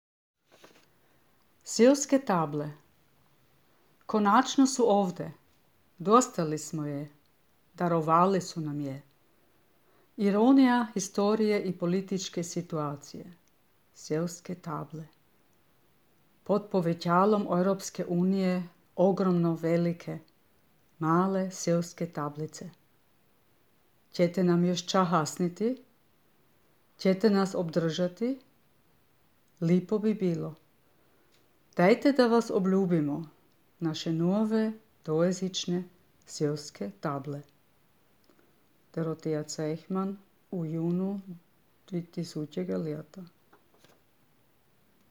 čita